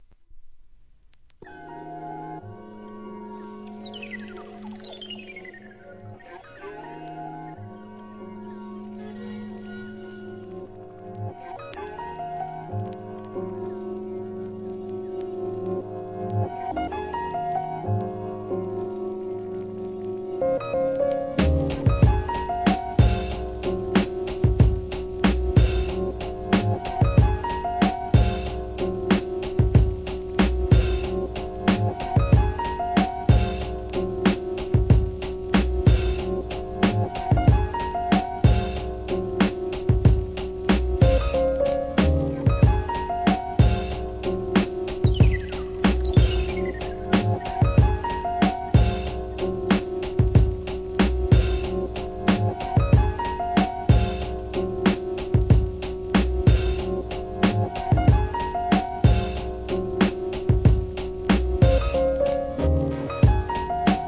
HIP HOP/R&B
JAZZ, SOULを軸にした秀逸なブレイクビ−ツEP!!!